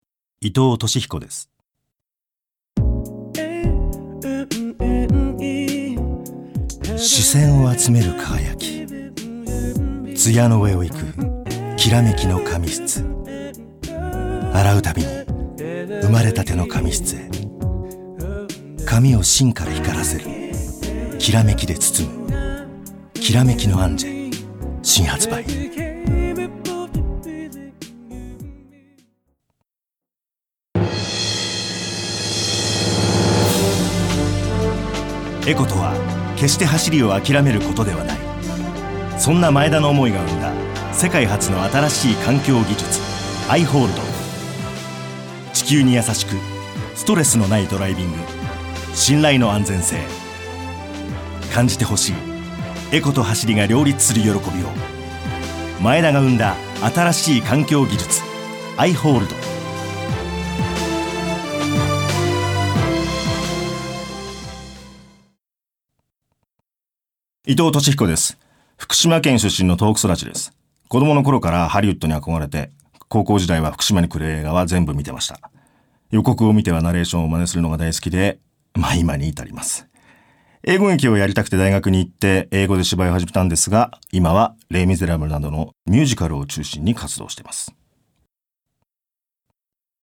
VOICE